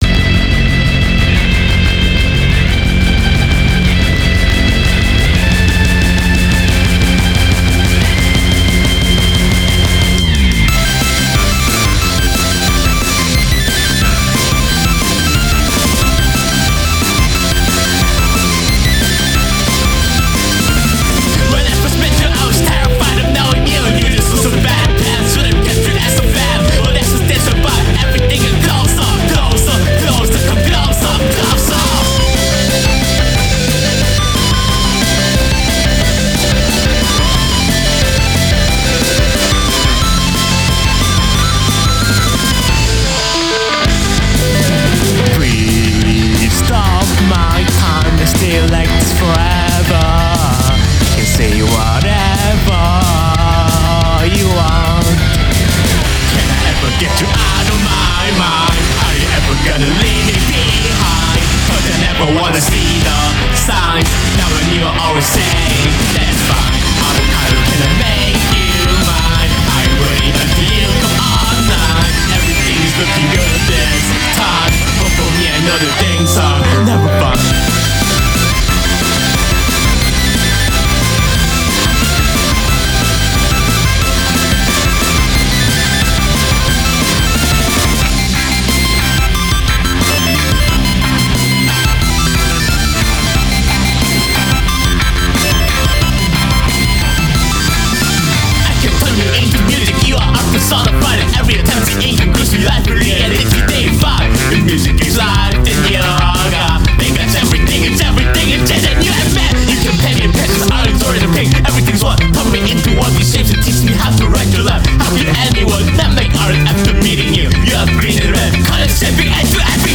It's an emo album about being a sad bunny.
Guitar, bass, bg vocals